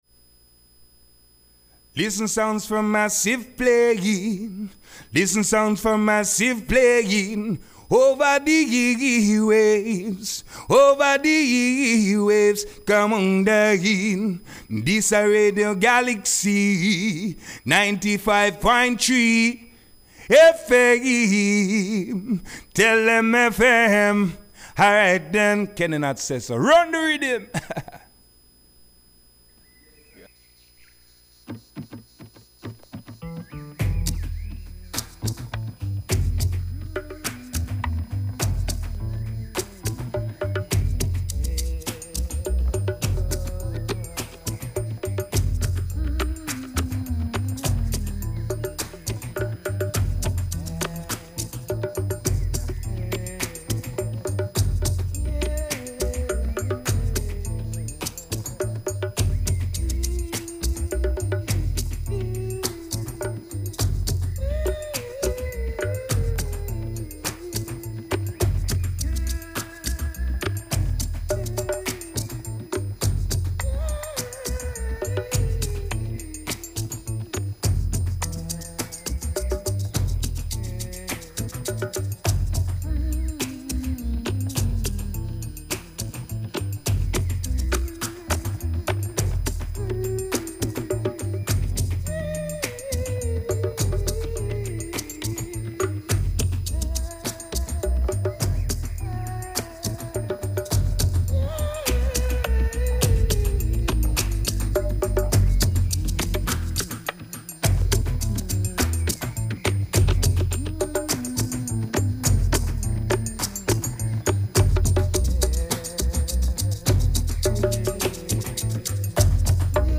reggaephonique
qui nous a concocté un super set hétéroclite et puissant.